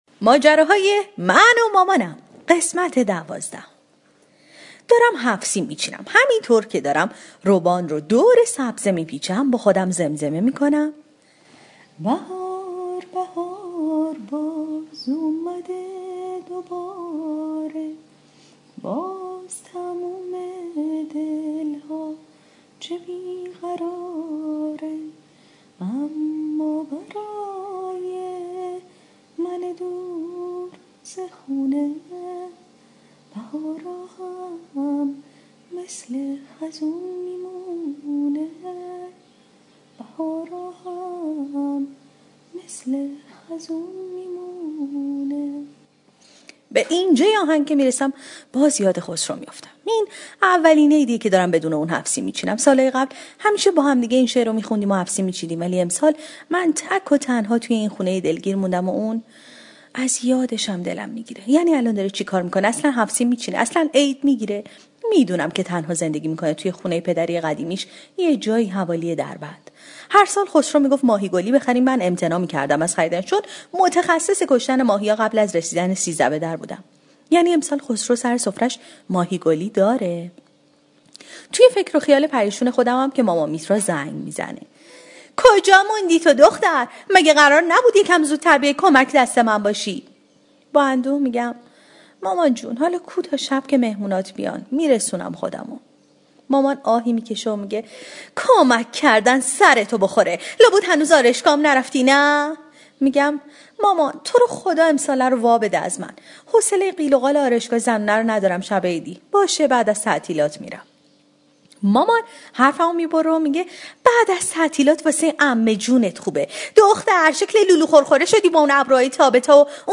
طنزصوتی/ ماجراهای من و مامانم ۱۲